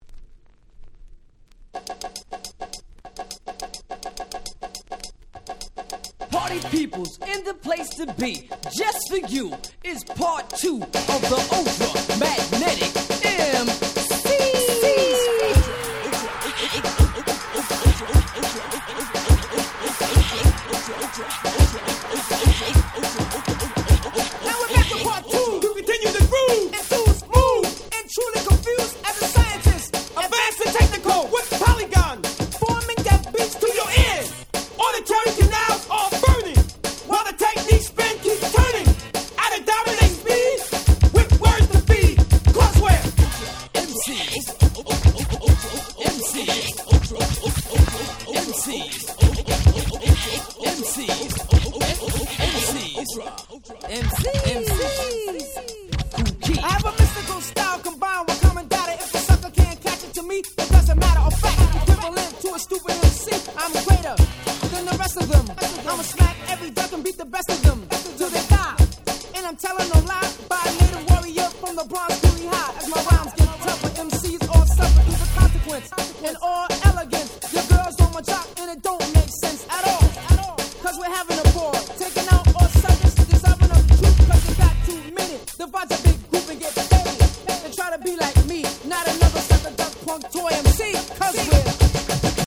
87' Hip Hop Classics !!
BPM速めでFunkyなBeatがクセになる超絶クラシック！！